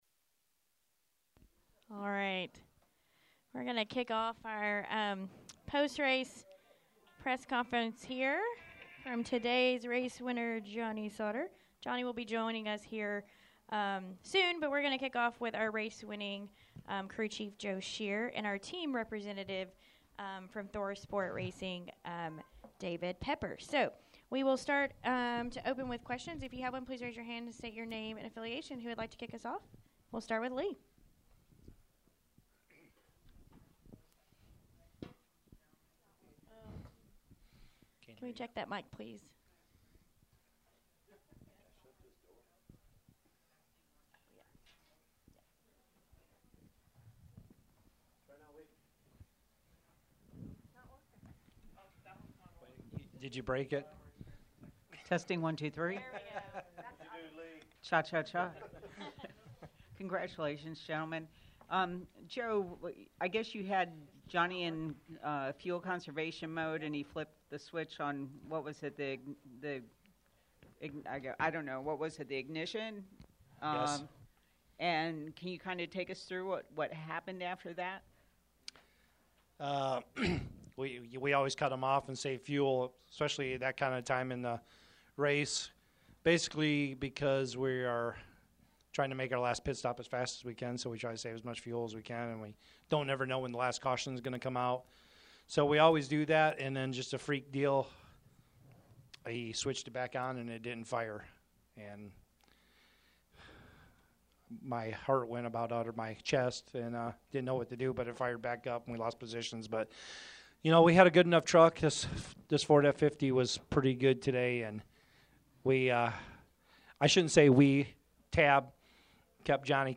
Media Center Interviews: